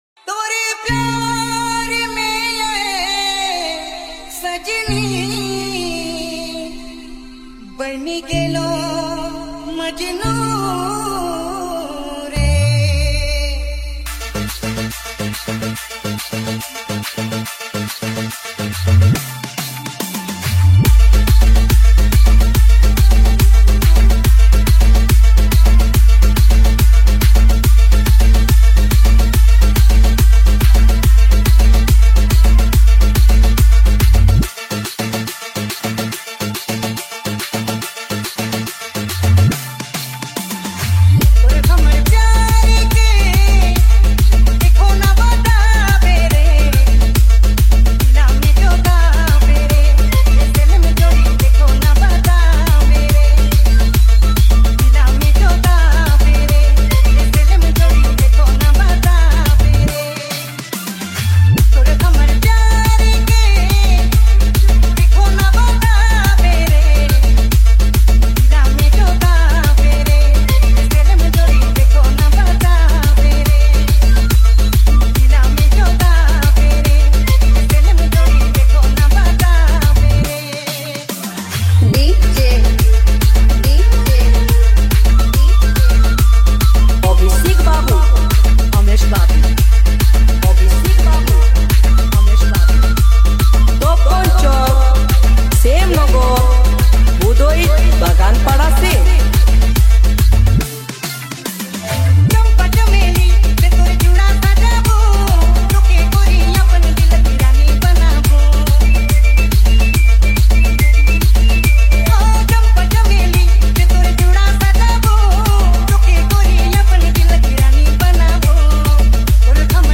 Dj Remixer
New Nagpuri Dj Song 2025